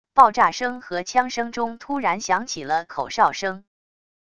爆炸声和枪声中突然响起了口哨声wav音频